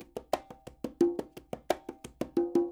SF LOOPS 176